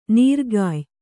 ♪ nīrgāy